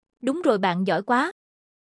Âm thanh Đúng Rồi Bạn Giỏi Quá (giọng Nữ, Miền Nam)
Thể loại: Hiệu ứng âm thanh
Description: Âm thanh “Đúng rồi, bạn giỏi quá” (giọng nữ miền Nam) là hiệu ứng khen ngợi vui nhộn, truyền cảm, thường được giáo viên mầm non sử dụng để khích lệ học sinh. Âm thanh mang sắc thái dễ thương, thân thiện, phù hợp cho video học tập, bài giảng điện tử, thiết kế Canva, hoặc slide PowerPoint.
am-thanh-dung-roi-ban-gioi-qua-giong-nu-mien-nam-www_tiengdong_com.mp3